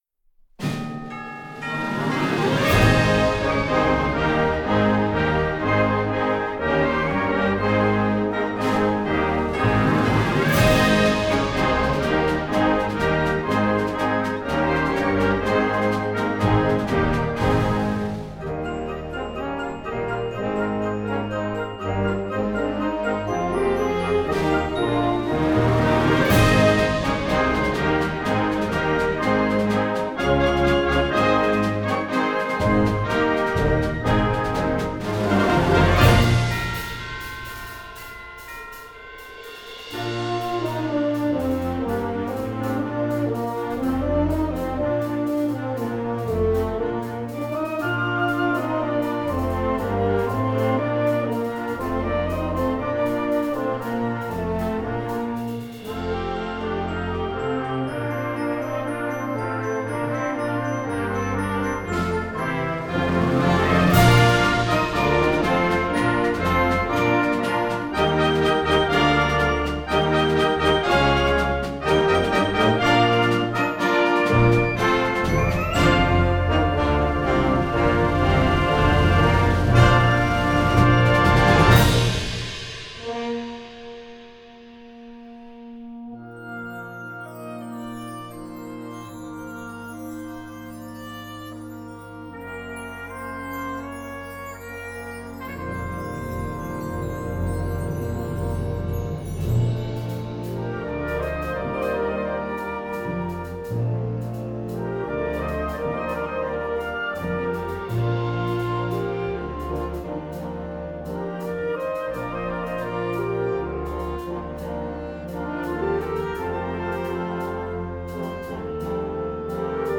Gattung: Weihnachtsmedley für Blasorchester
Besetzung: Blasorchester
Ein dynamisches Medley, das die Weihnachtszeit einläutet!